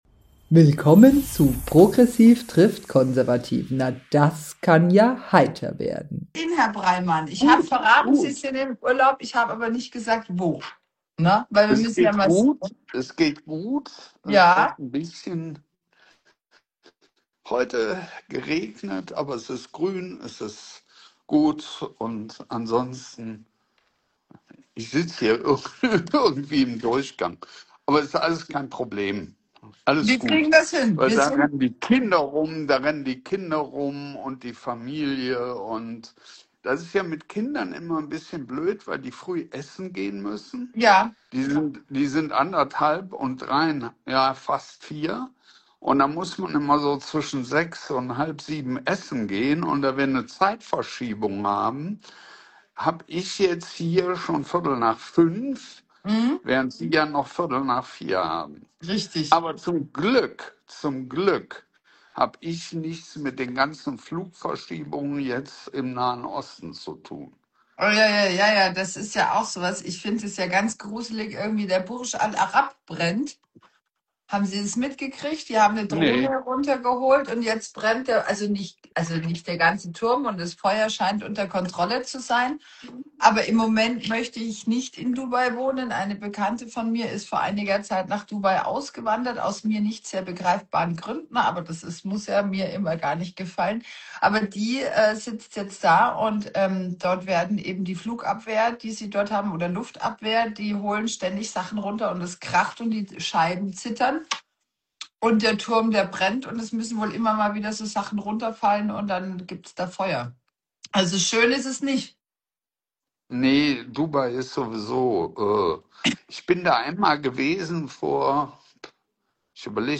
Wochenrückblick